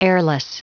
Prononciation du mot heirless en anglais (fichier audio)
Prononciation du mot : heirless